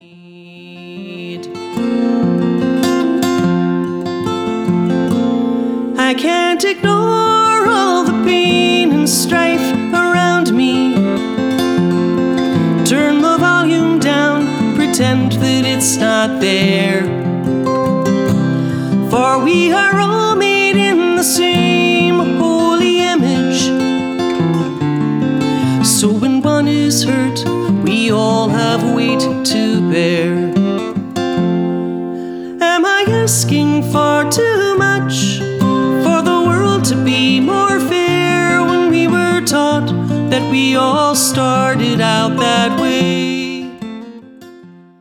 Jewish folk style